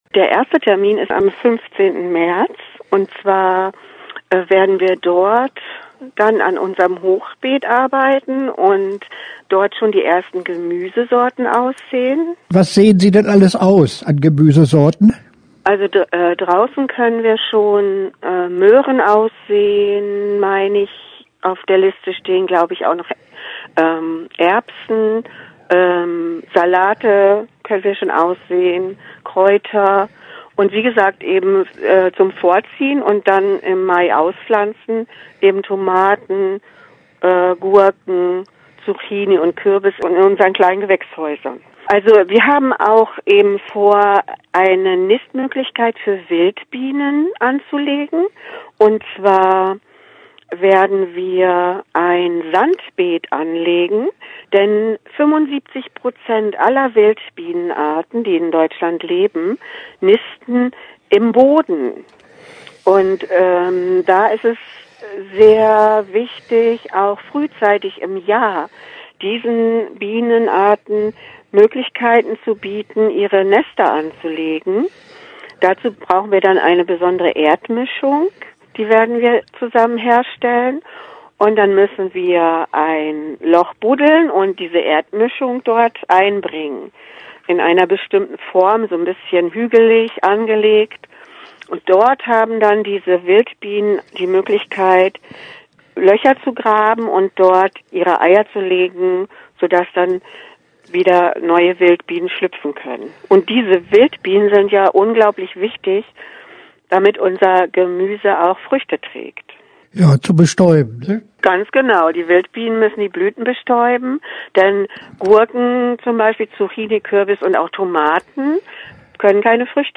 Interview-Garten-Workshop.mp3